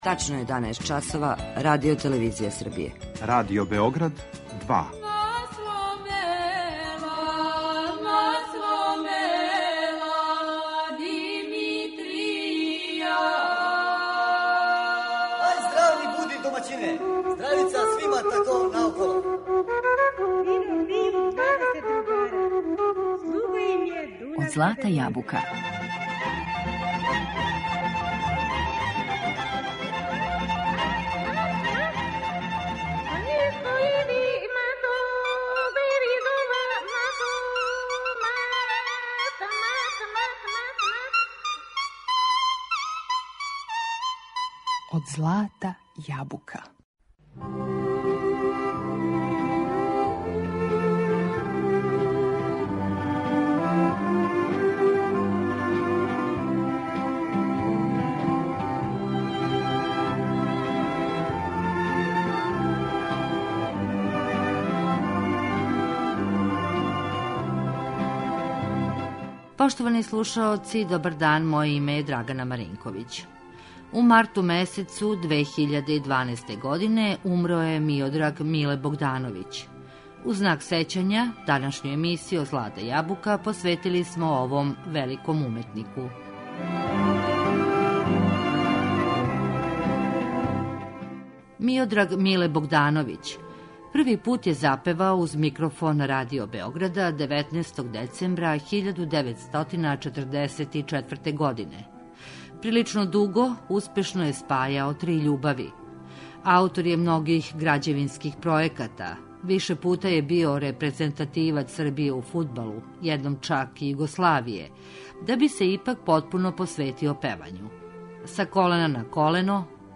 Разговор са уметником, који ћемо слушати у данашњој емисији, забележен је у децембру 2004, поводом обележавања шездесет година уметничког рада.